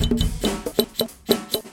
drum.wav